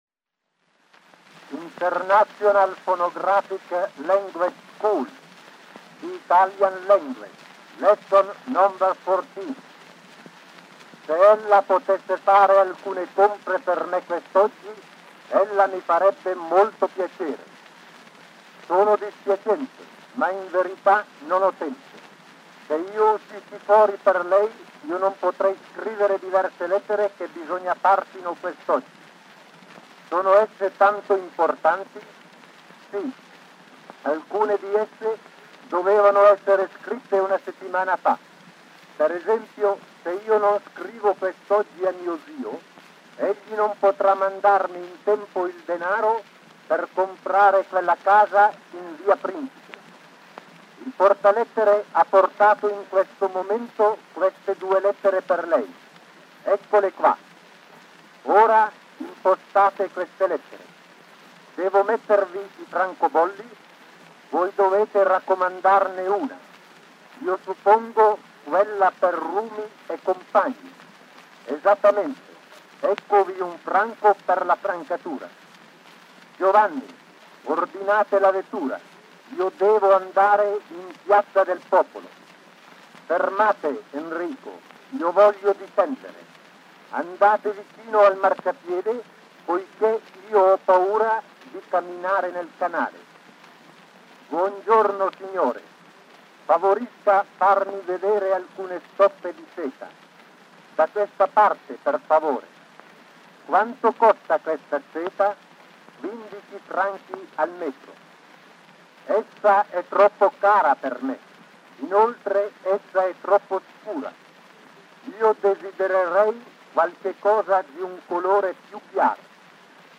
Foreign language lessons.
Italian language—Sound recordings for English speakers.